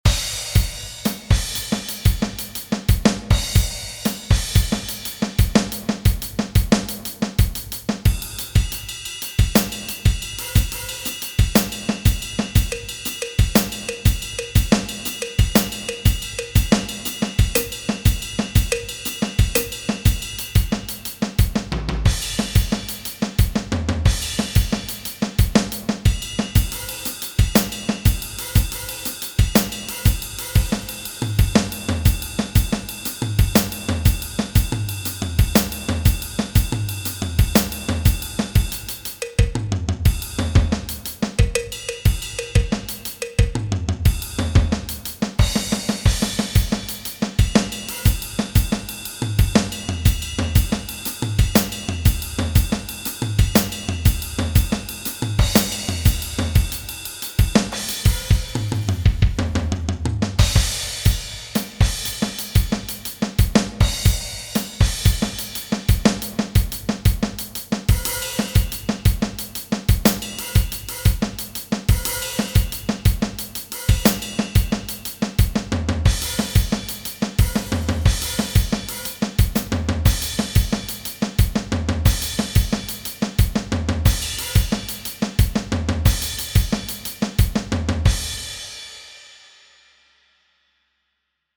Rabbit Hole, part the 3, is what happened when I altered the Double Paradiddle rudiment, from RLRLRR to RLRRLR and LRLRRL, then orchestrated it 20+ different ways around the drum kit, playing triplets over a 4/4 (RLR-RLR-LRL-RRL).
Notations here: Rabbit Hole III.pdf and also the mp3 from a MIDI recording of those same notations.
double paradiddle, drum, drumming, music, notations, paradiddle, playthrough, Rabbit Hole